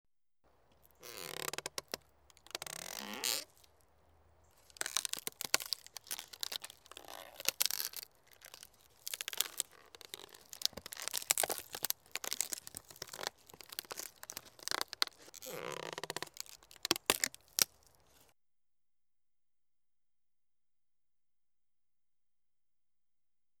Scary Sounds - 14 - Coffin Opening Type